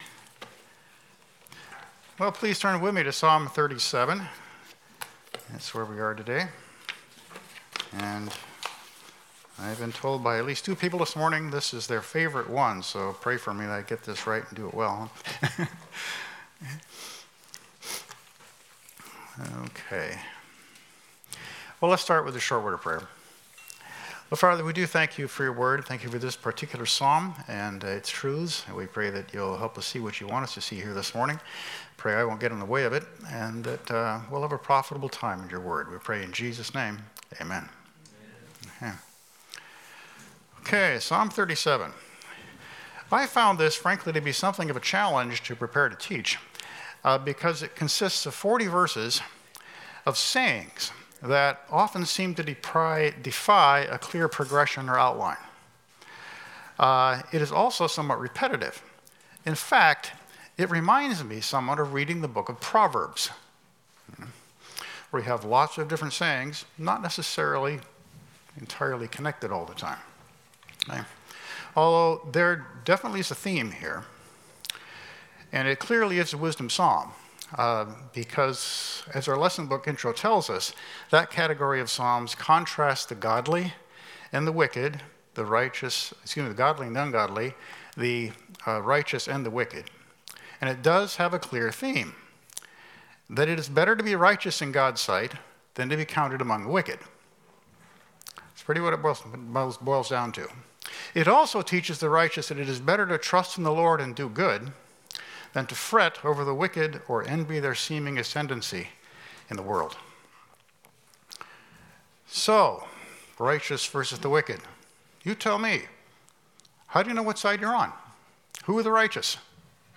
Paslm 37 Service Type: Sunday School « A Mighty Demon Deliverance Word